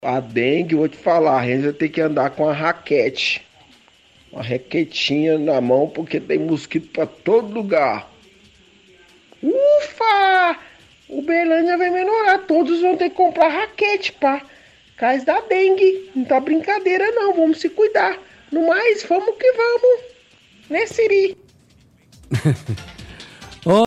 -Ouvinte debocha dizendo que todos vão ter que comprar raquete por causa da dengue, diz que tem mosquito por todos os lugares.